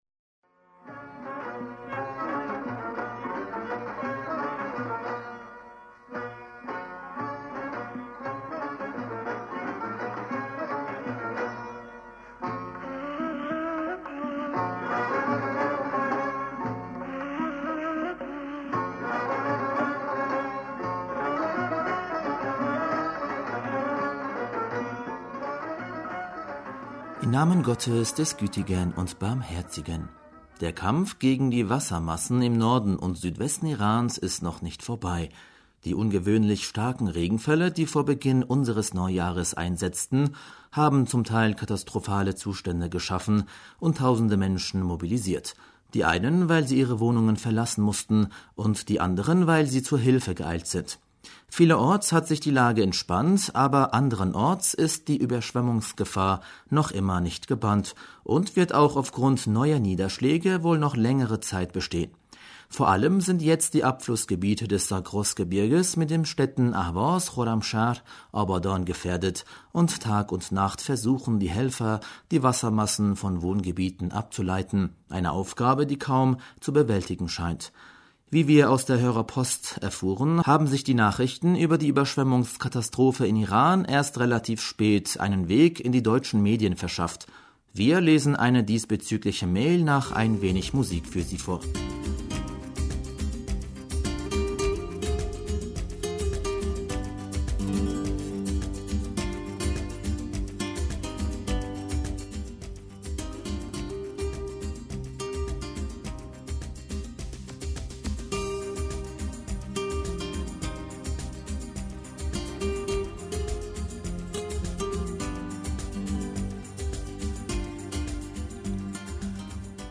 Hörerpostsendung am 14. April 2019 - Bismillaher rahmaner rahim - Der Kampf gegen die Wassermassen im Norden und Südwesten Irans ist noch nicht vorbei.
Wir lesen eine diesbezügliche Mail nach ein wenig Musik für Sie vor.